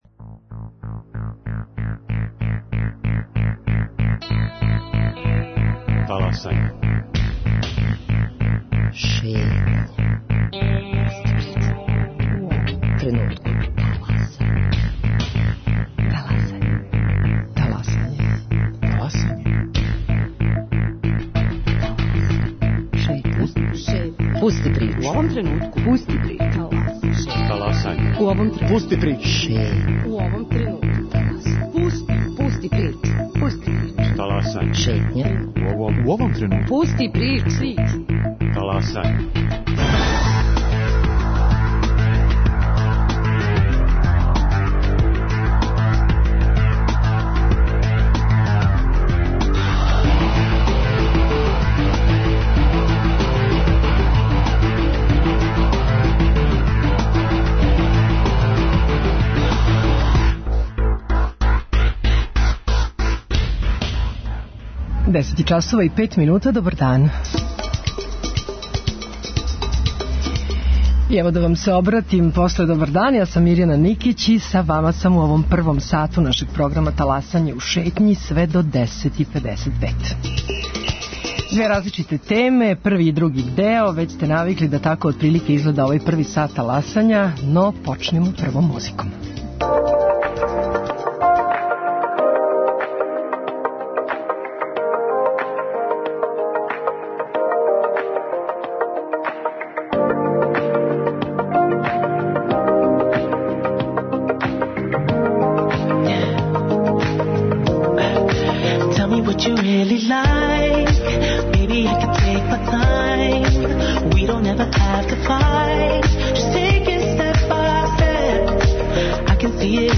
Посебно значајна је идеја активног промовисања повратка природи и здравим стиловима живота у свим овим областима, о чему ће говорити наши гости, чланови удружења ШУША.